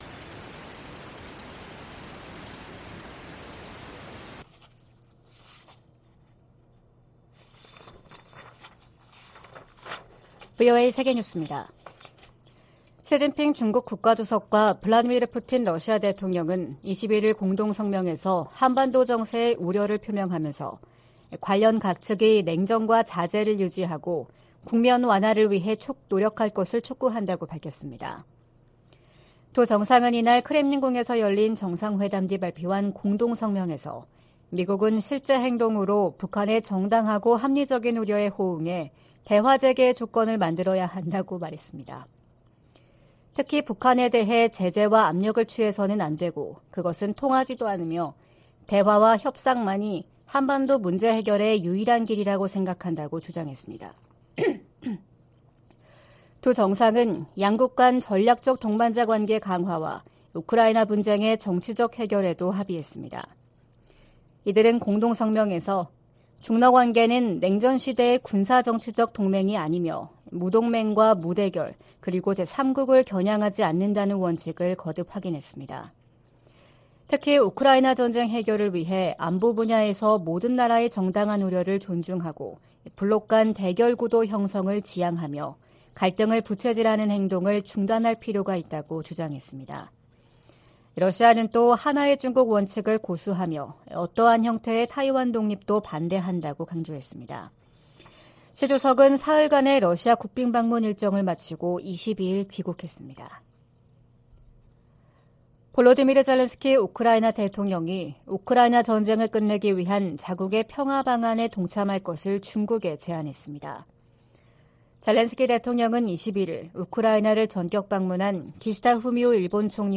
VOA 한국어 '출발 뉴스 쇼', 2023년 3월 23일 방송입니다. 북한이 또 다시 순항미사일 여러 발을 동해상으로 발사했습니다. 미국 정부는 모의 전술핵 실험에 성공했다는 북한의 주장에 우려를 표했습니다. 미국 국방부가 북한의 미사일 프로그램이 제기하는 도전을 면밀히 감시하고 있다고 강조했습니다.